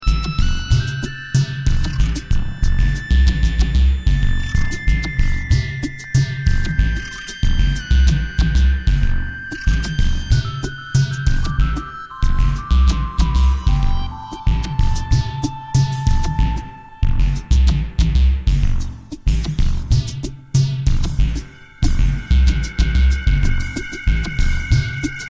Outsider Dance
Electro Electronix